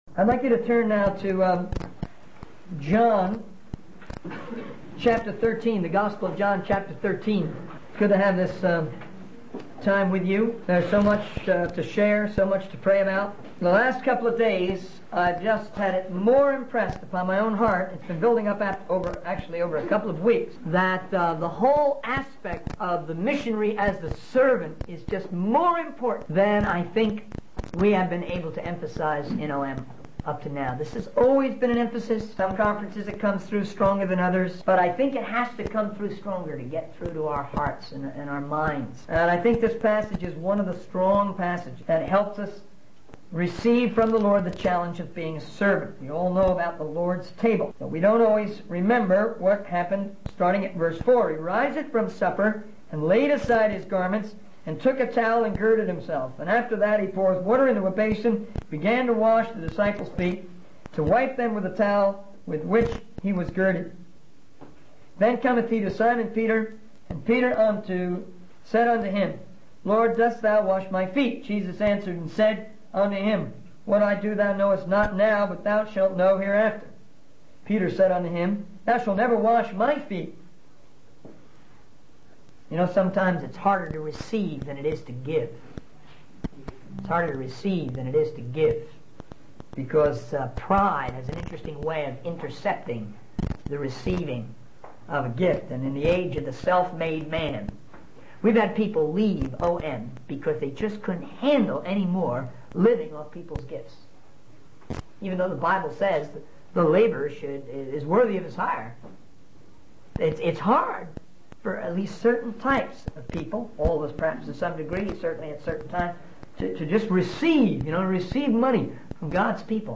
In this sermon, the speaker emphasizes the importance of serving others, using the passage from John 13:4-5 as a strong example. The speaker highlights how Jesus humbly washed the disciples' feet, demonstrating the challenge of being a servant.